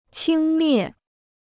轻蔑 (qīng miè): scornful; disdainful; contemptuous; pejorative; disdain; contempt.